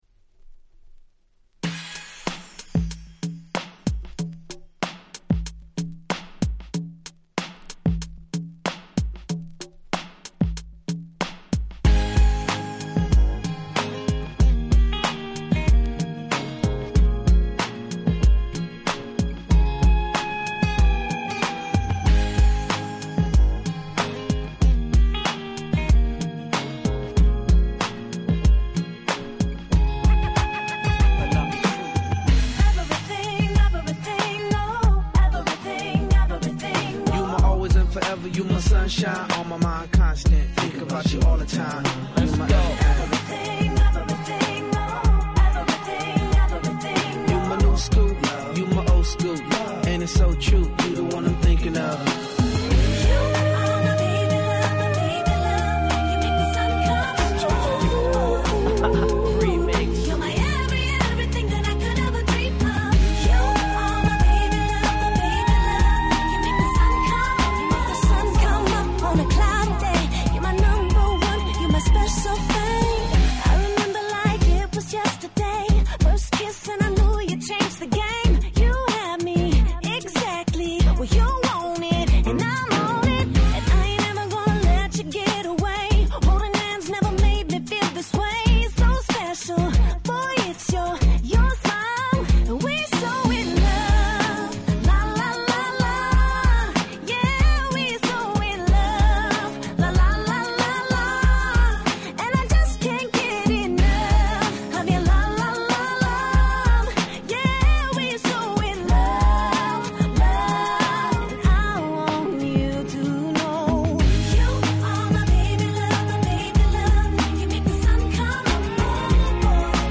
07' Smash Hit R&B♪